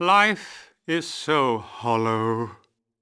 vs_fxanxxxx_help.wav